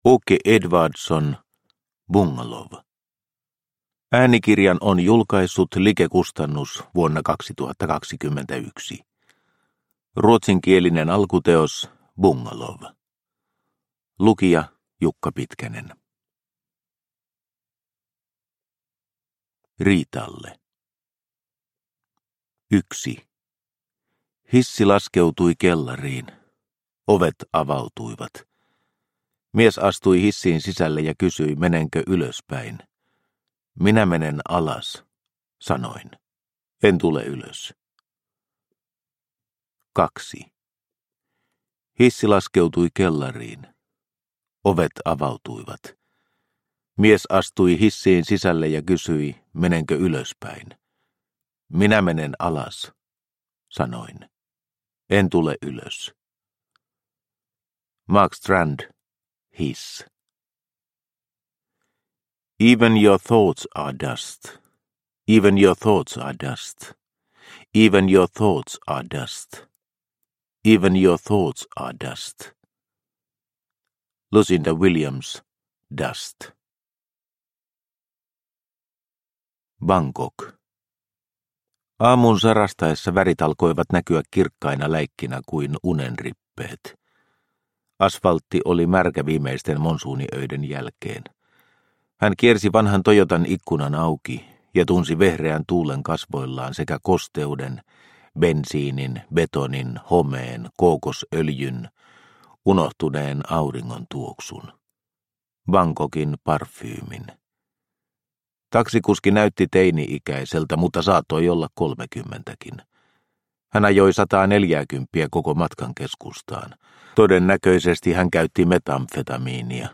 Bungalow – Ljudbok – Laddas ner